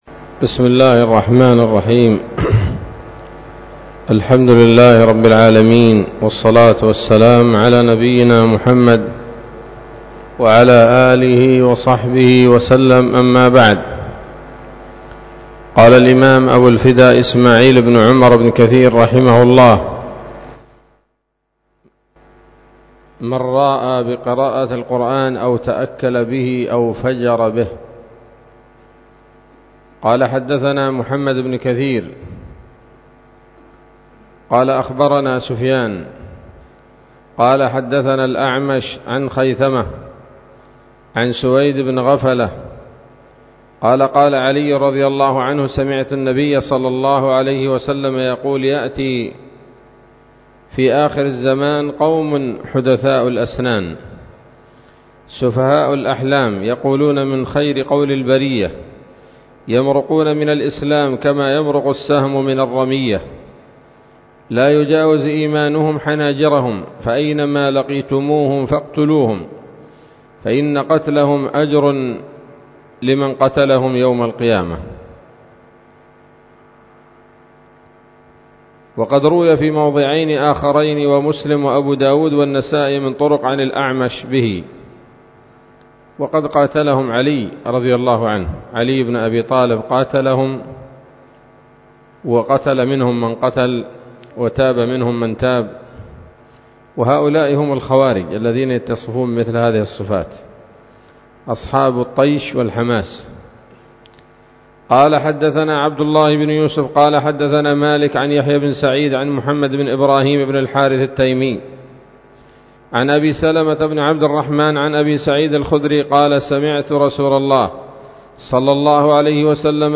الدرس الثلاثون من المقدمة من تفسير ابن كثير رحمه الله تعالى